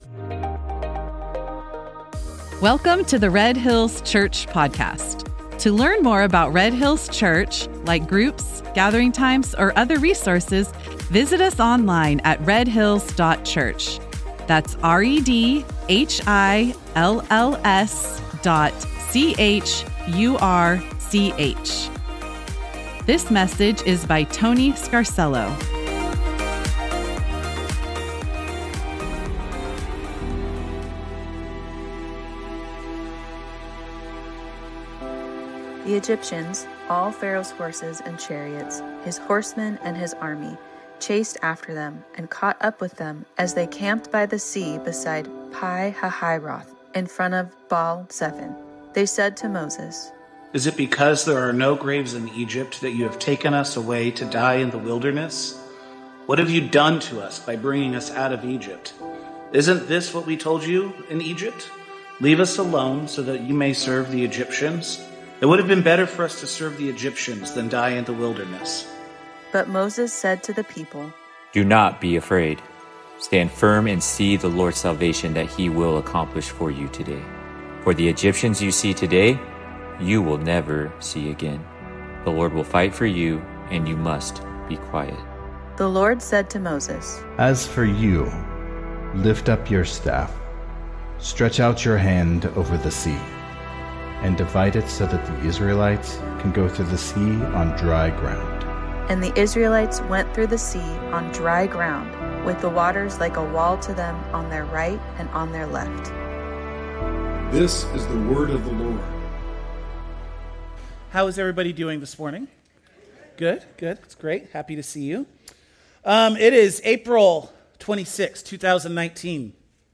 1 EZRA LEVANT | Mark Carney rolls out the red carpet for Trump at G7 46:51 Play Pause 7d ago 46:51 Play Pause Play later Play later Lists Like Liked 46:51 The Rebel News podcasts features free audio-only versions of select RebelNews+ content and other Rebel News long-form videos, livestreams, and interviews. Monday to Friday enjoy the audio version of Ezra Levant's daily TV-style show, The Ezra Levant Show, where Ezra gives you his contrarian and conservative take on free speech, politics, and foreig…